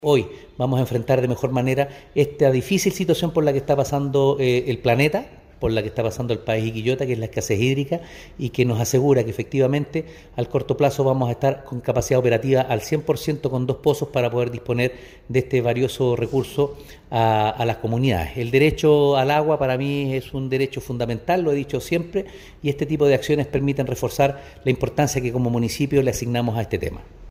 alcalde-pozo.mp3